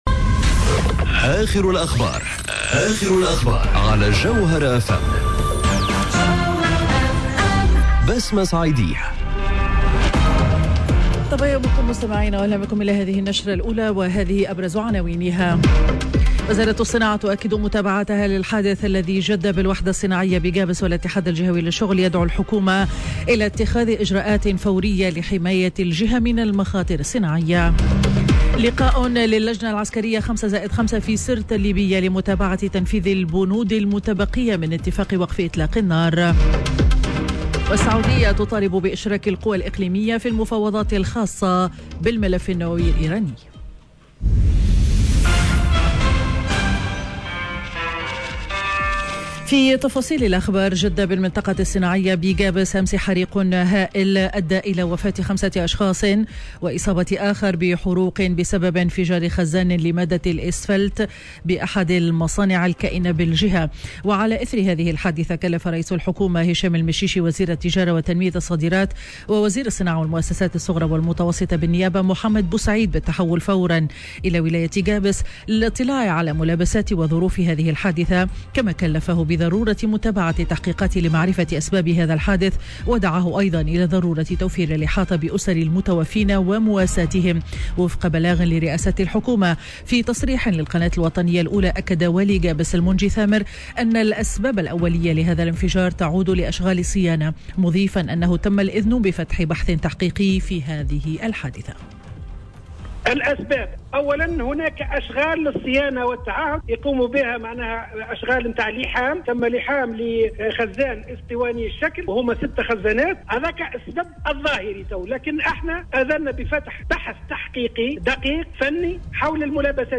نشرة أخبار السابعة صباحا ليوم الأحد 14 مارس 2021